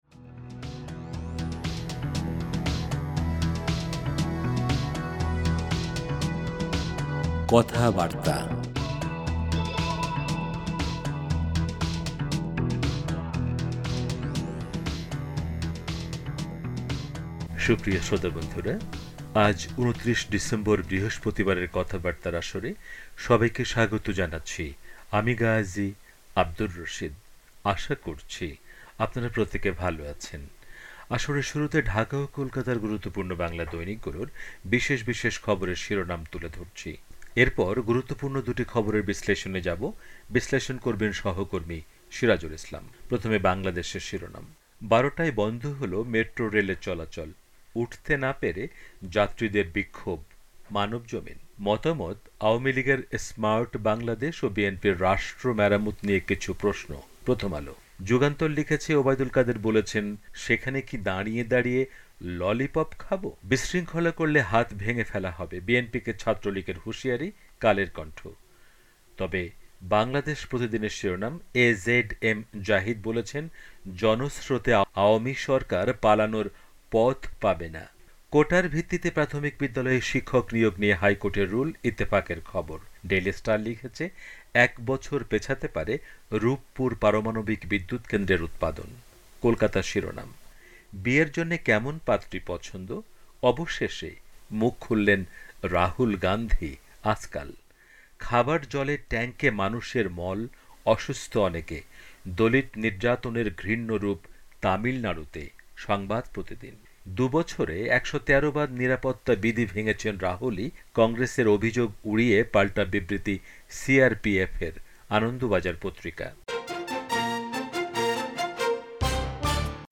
আসরের শুরুতে ঢাকা ও কোলকাতার গুরুত্বপূর্ণ বাংলা দৈনিকগুলোর বিশেষ বিশেষ খবরের শিরোনাম তুলে ধরছি। এরপর গুরুত্বপূর্ণ দুটি খবরের বিশ্লেষণে যাবো।